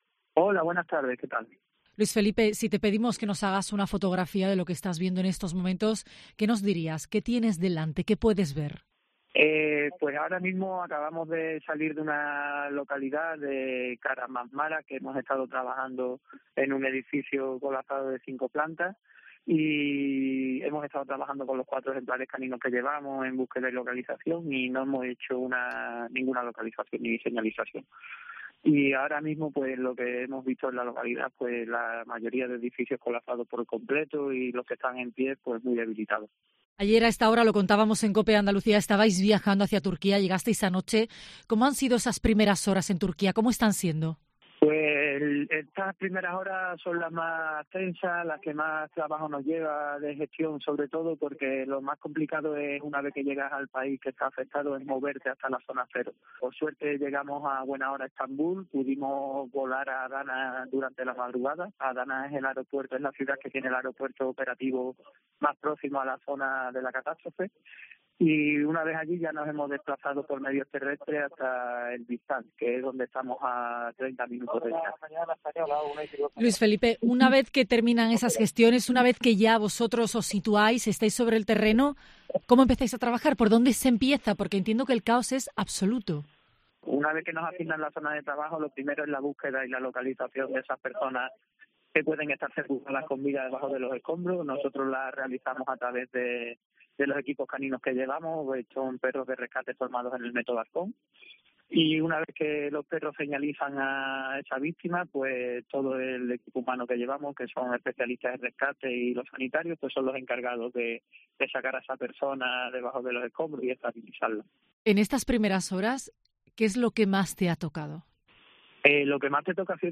Y lo que le queda: “No importa, ya habrá tiempo de dormir”, nos dice mientras viaja en una camioneta en busca de desaparecidos.